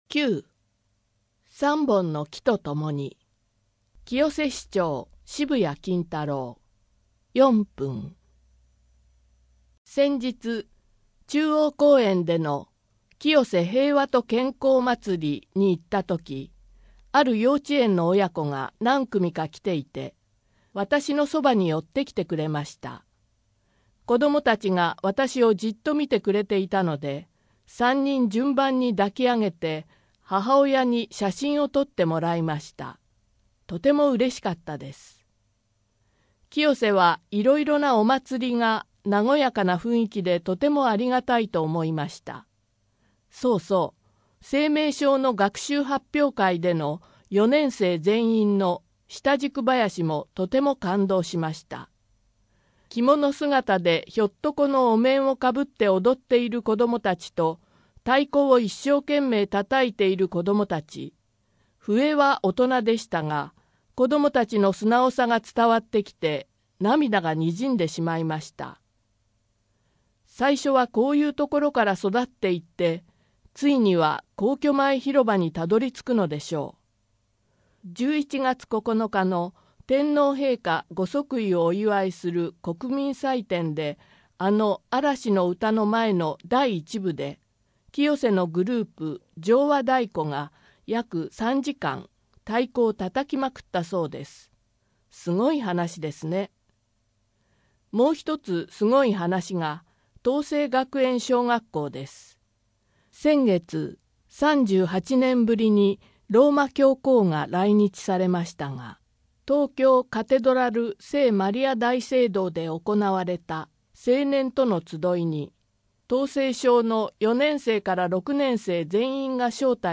声の広報 声の広報は清瀬市公共刊行物音訳機関が制作しています。